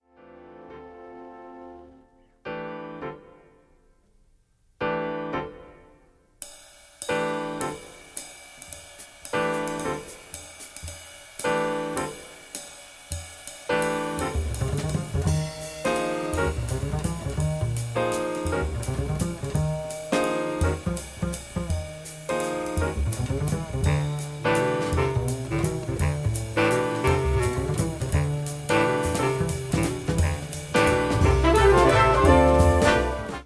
Change the meter 4/4 to 3/4.
Changing the meter is a great way to add variety.